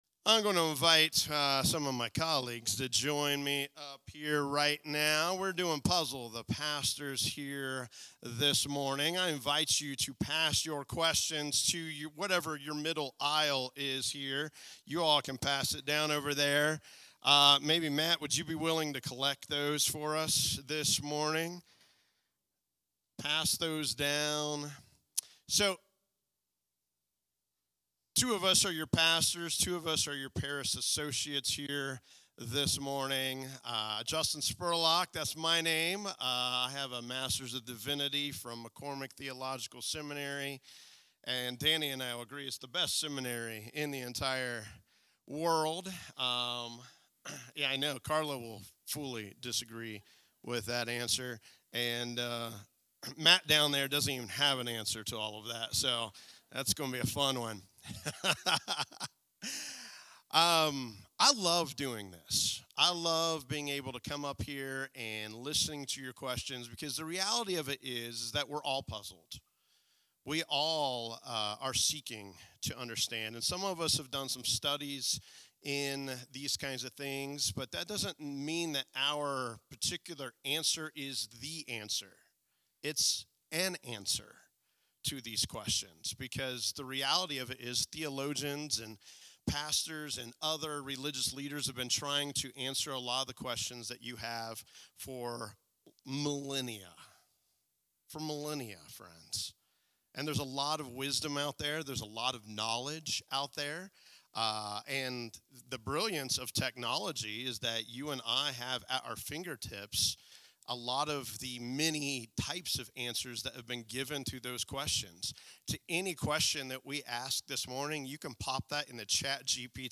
This week, members of our congregation put four of our pastors and parish associates to the test with thoughtful, puzzling questions, sparking honest conversation and reflection on faith and everyday life.